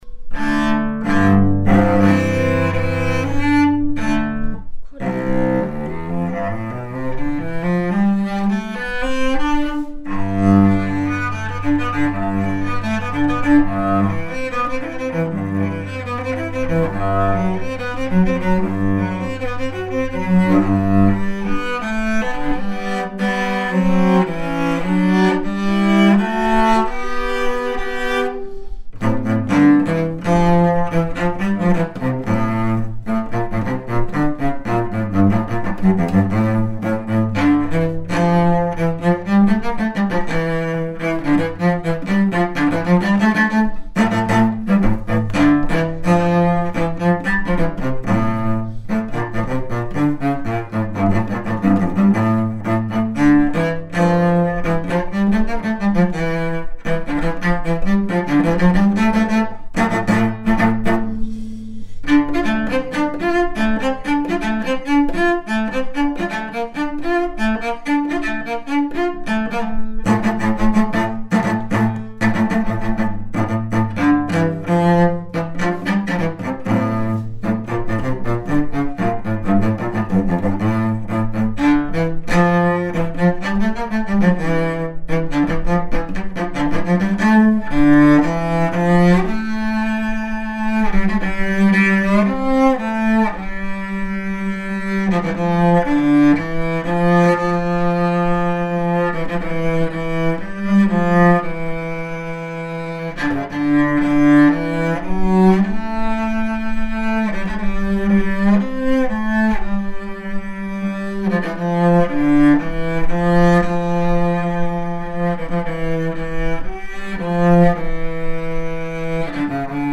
演奏者紹介)さんをお迎えし、試奏と以下のそれぞれのチェロの講評をして頂きました。